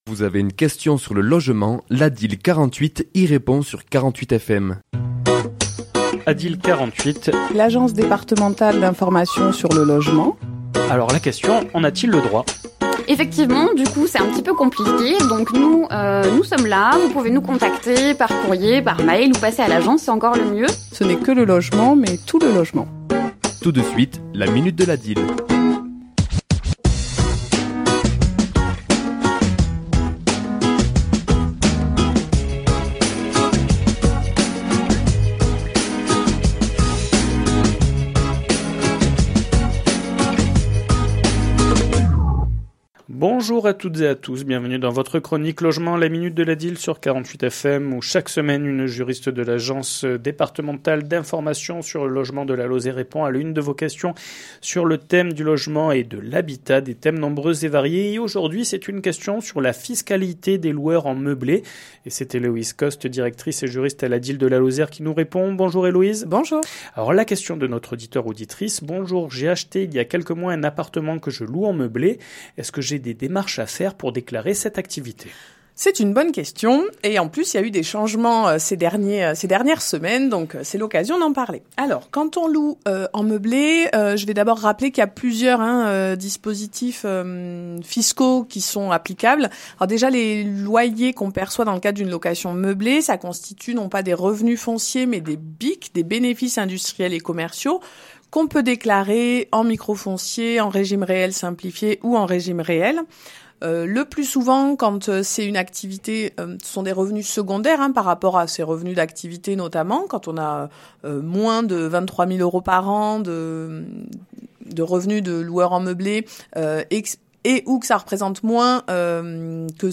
ChroniquesLa minute de l'ADIL
Chronique diffusée le mardi 04 avril à 11h00 et 17h10